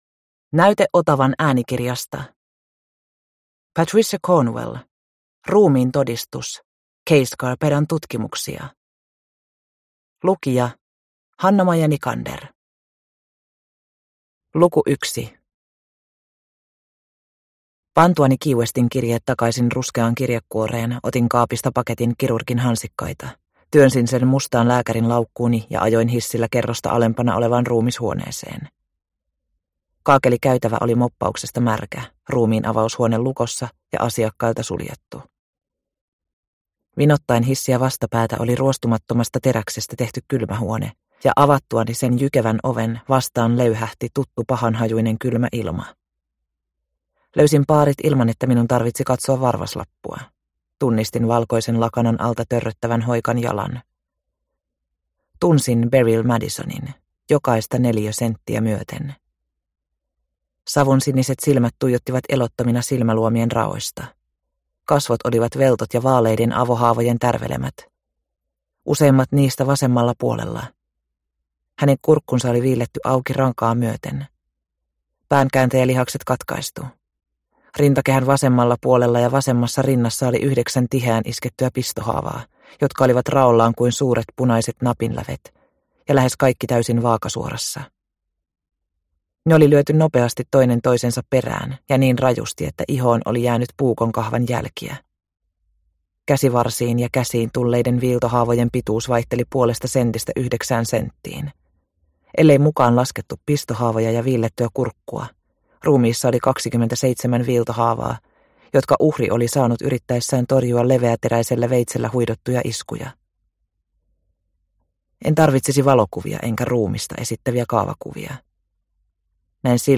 Ruumiin todistus – Ljudbok – Laddas ner